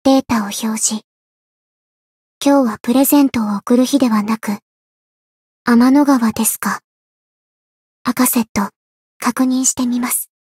灵魂潮汐-阿卡赛特-七夕（送礼语音）.ogg